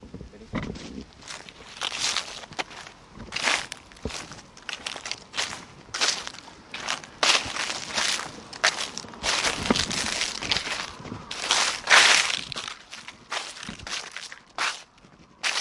描述：嘎吱嘎吱的一片叶子。
Tag: 叶紧缩 WAV 秋天 树叶 死叶